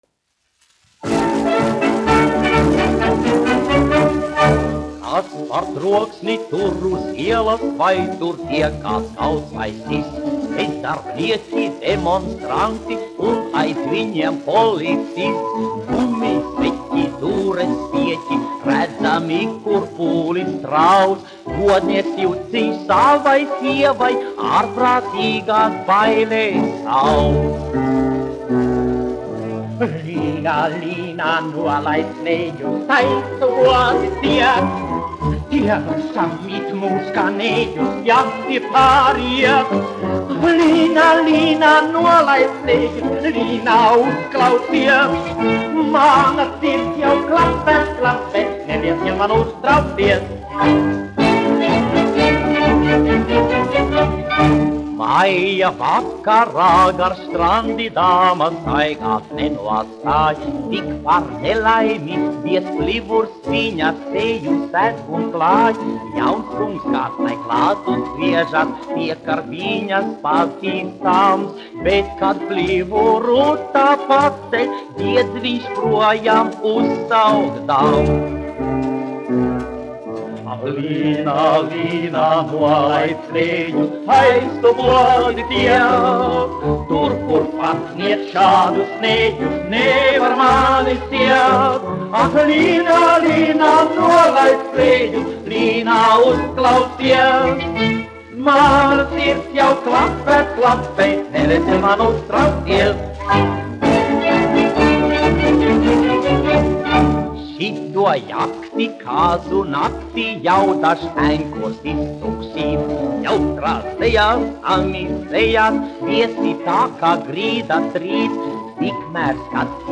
1 skpl. : analogs, 78 apgr/min, mono ; 25 cm
Populārā mūzika
Humoristiskās dziesmas